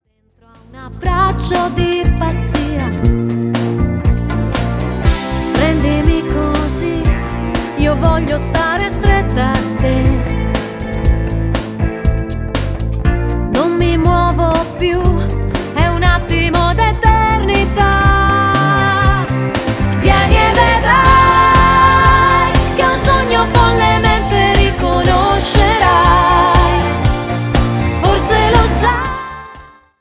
Moderato (3.59) Base Testo Strum.